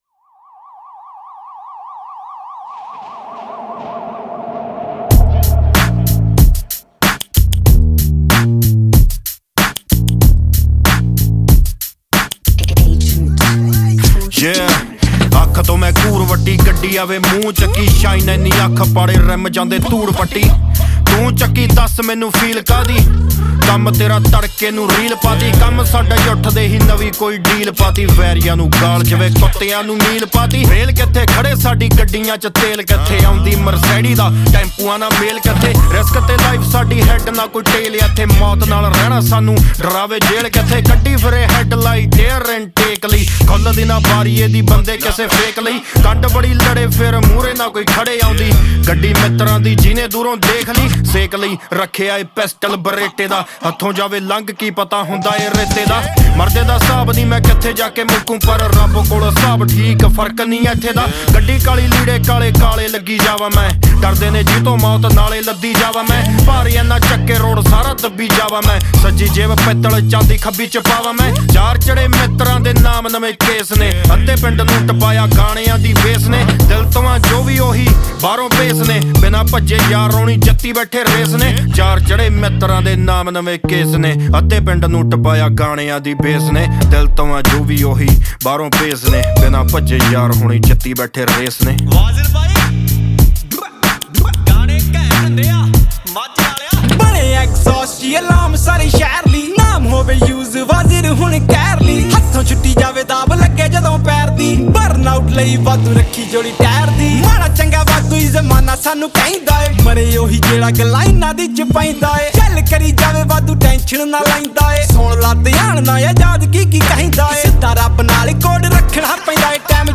New Punjabi Song